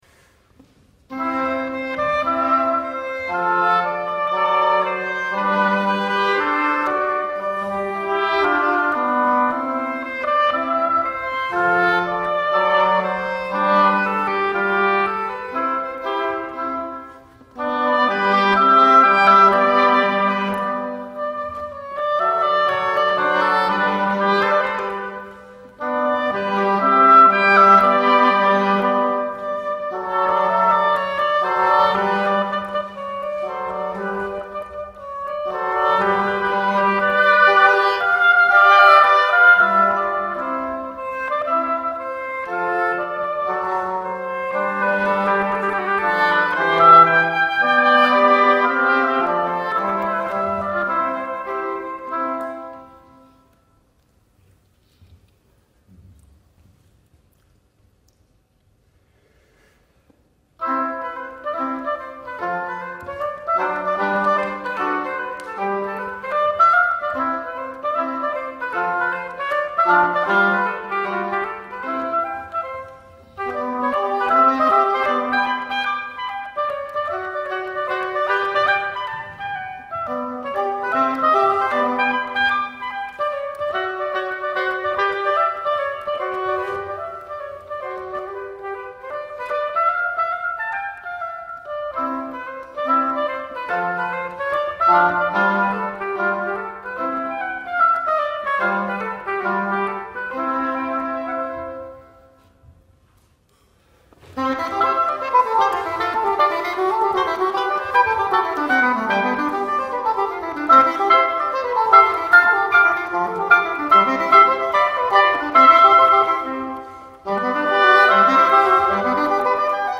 A group of three musicians.
Oboe trio